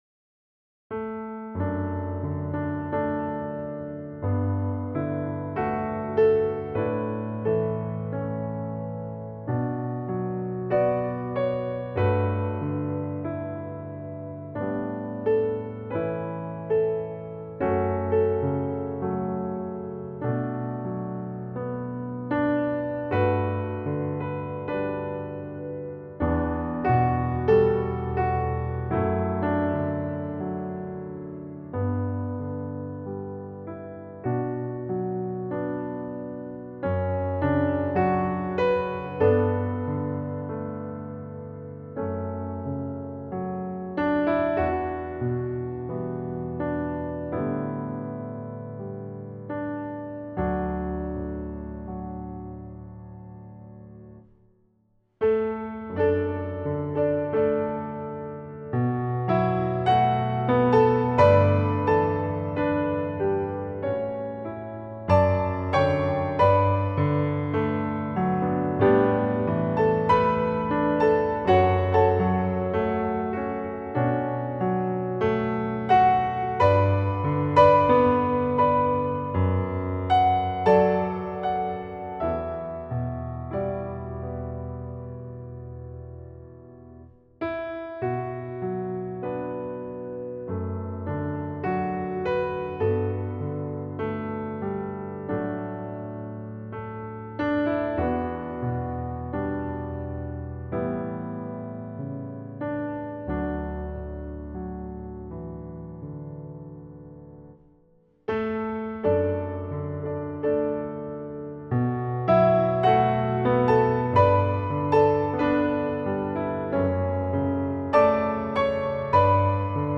lb-shenandoah (variazioni sul tema).m4a